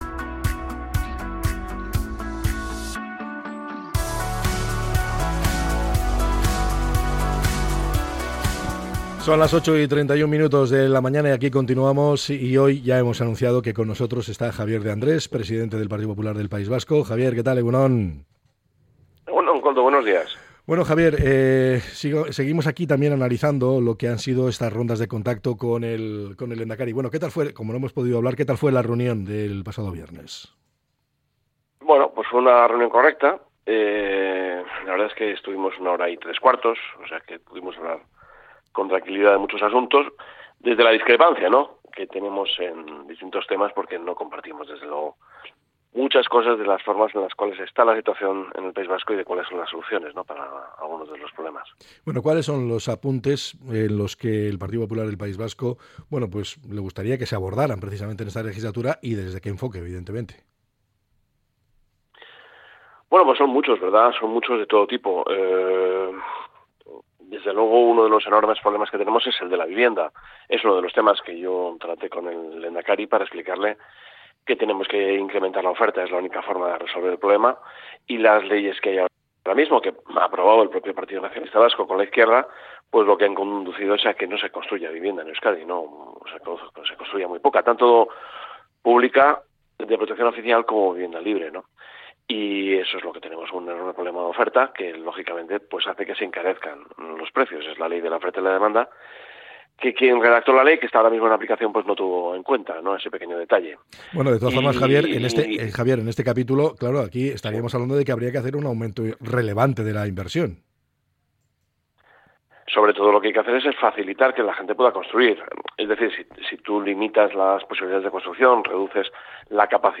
Entrevista con Javier De Andrés, líder de los populares vascos